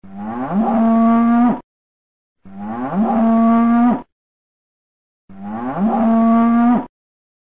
Звуки коровы
Мычание быка